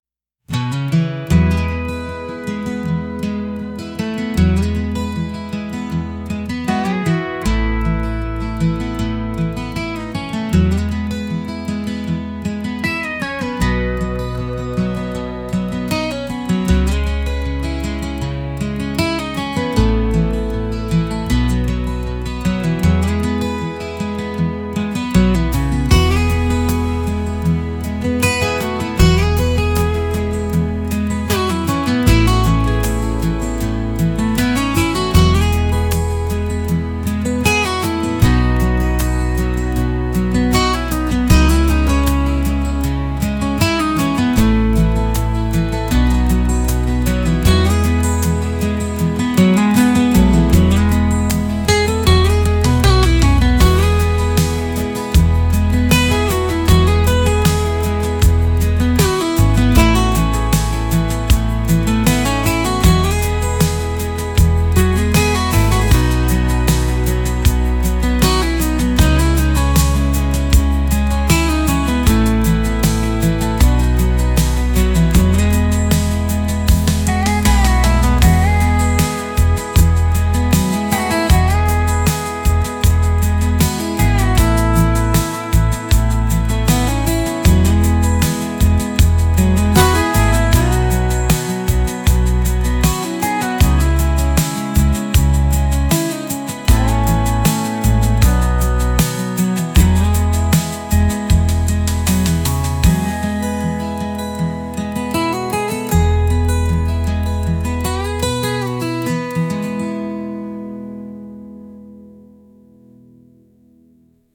Genre: Country Mood: Acoustic Editor's Choice